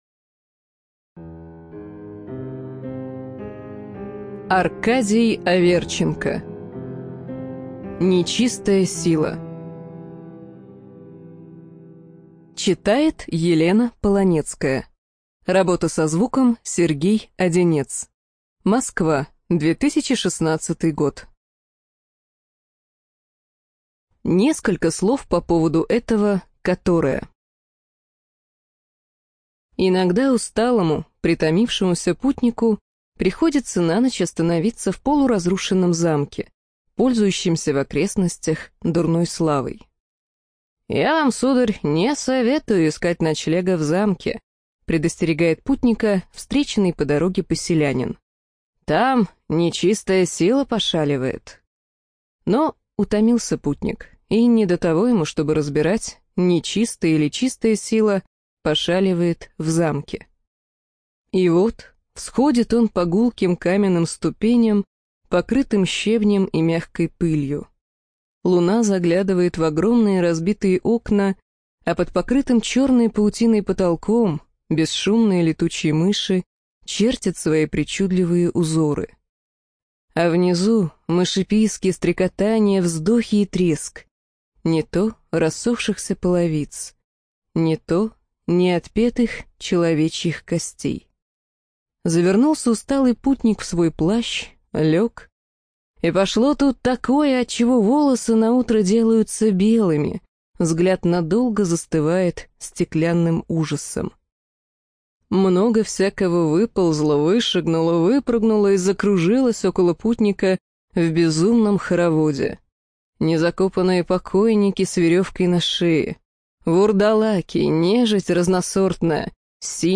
Эту и другие книги нашей библиотеки можно прослушать без использования компьютера с помощью Android-приложения или тифлофлешплеера с поддержкой онлайн-доступа.